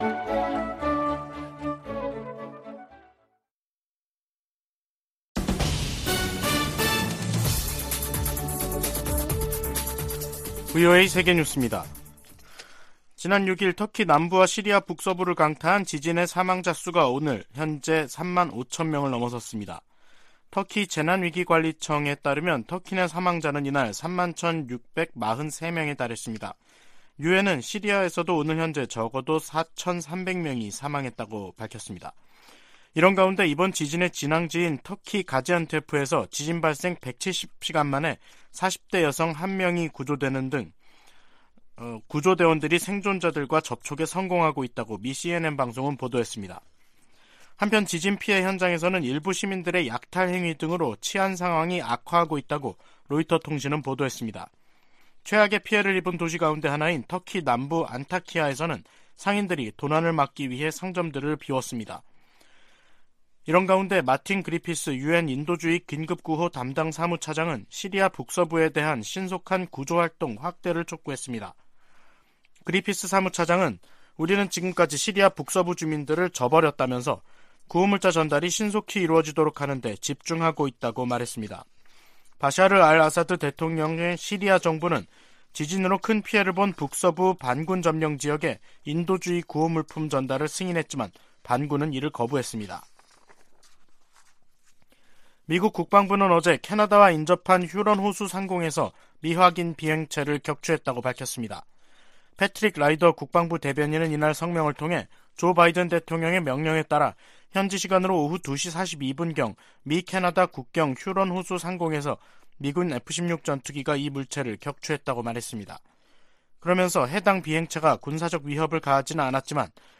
VOA 한국어 간판 뉴스 프로그램 '뉴스 투데이', 2023년 2월 13일 3부 방송입니다. 미 국무부는 북한이 고체연료 ICBM을 공개한 것과 관련해, 외교적 관여를 통한 한반도 비핵화 의지에 변함이 없다는 입장을 밝혔습니다. 북한과 러시아 간 군사협력이 한반도에도 좋지 않은 영향을 끼칠 것이라고 백악관이 지적했습니다. 한국의 남북이산가족협회가 북한 측으로부터 이산가족 문제를 토의하자는 초청장을 받았다며 방북을 신청했습니다.